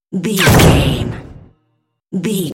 Dramatic door slam hit ricochet
Sound Effects
heavy
intense
dark
aggressive
hits